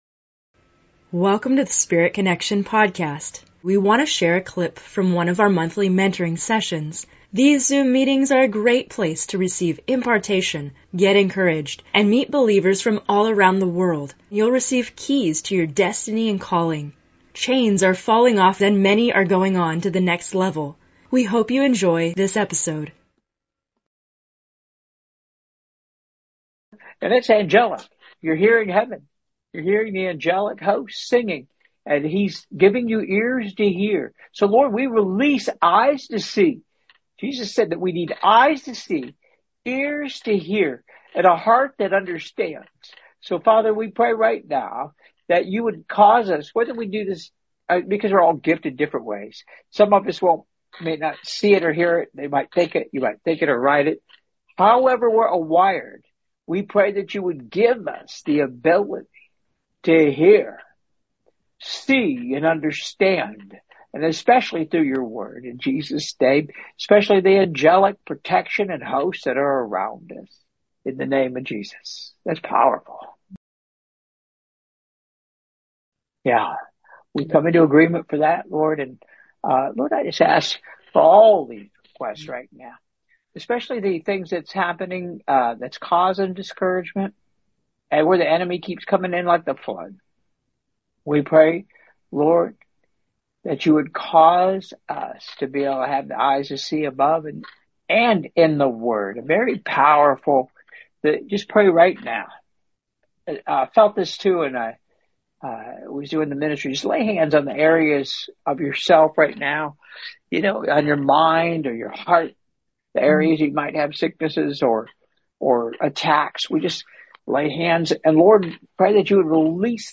In this episode of Spirit Connection, we have a special excerpt from a recent Monthly Mentoring Session.